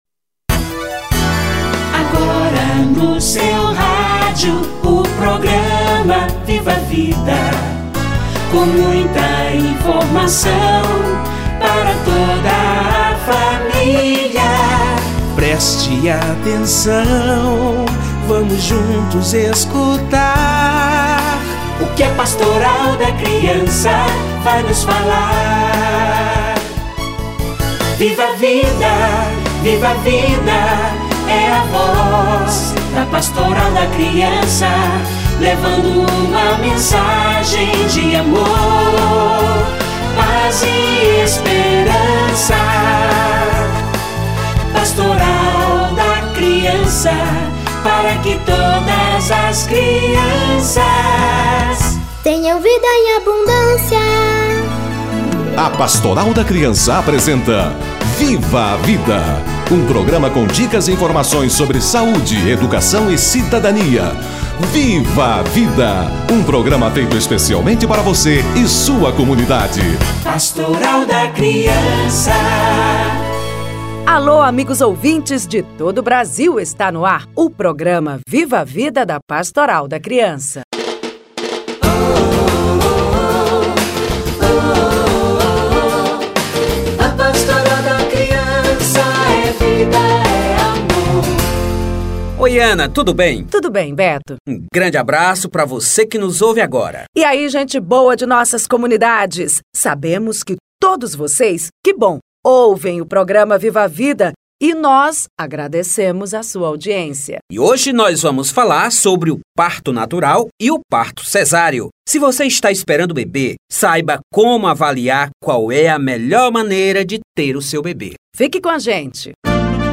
Parto normal e cesárea - Entrevista